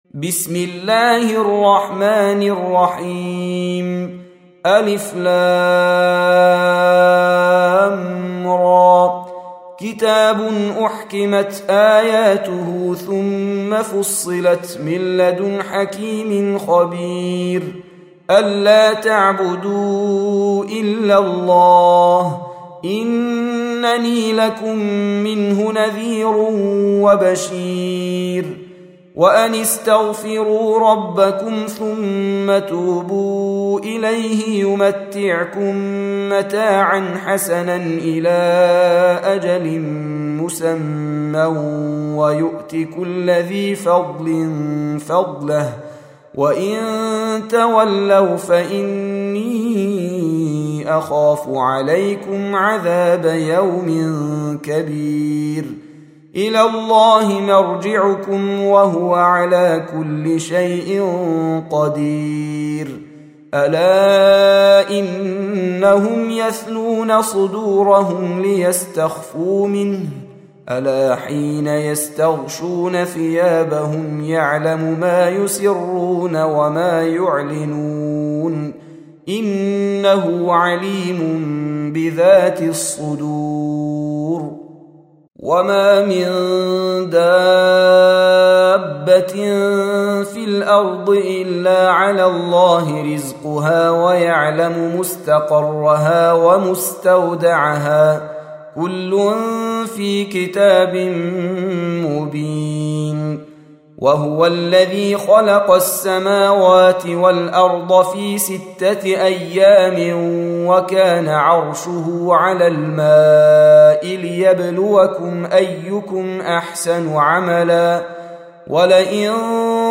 11. Surah H�d سورة هود Audio Quran Tarteel Recitation
Surah Repeating تكرار السورة Download Surah حمّل السورة Reciting Murattalah Audio for 11.